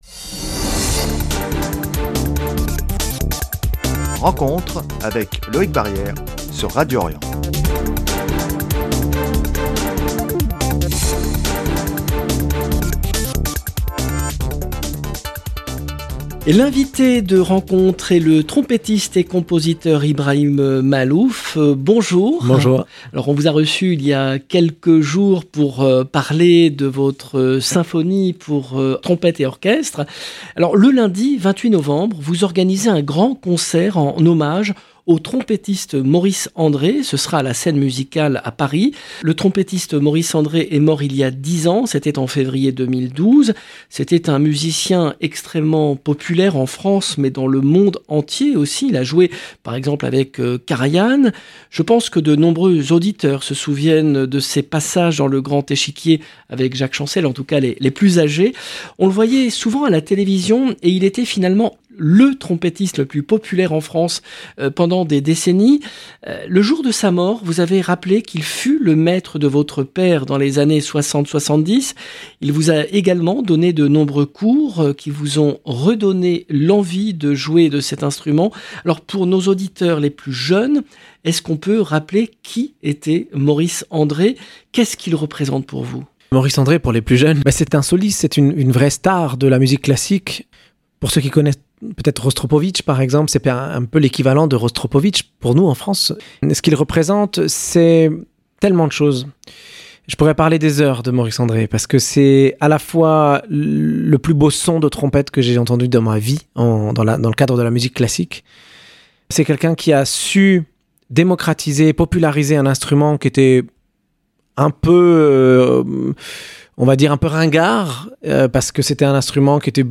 Pour nous en parler, le trompettiste et compositeur Ibrahim Maalouf est l’invité de RENCONTRE